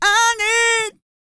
E-GOSPEL 135.wav